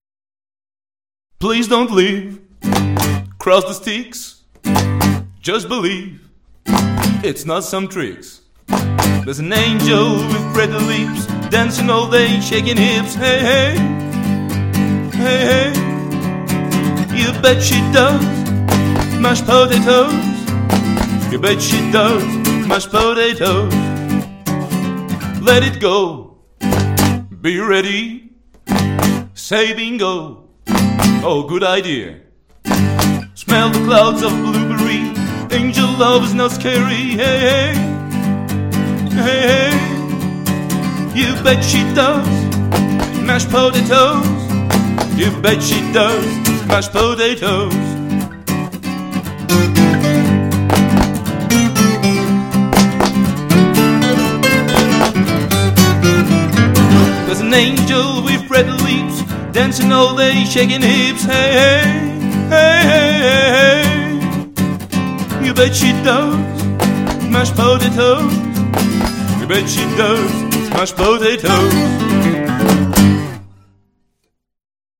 EXTRAITS MUSICAUX DU SPECTACLE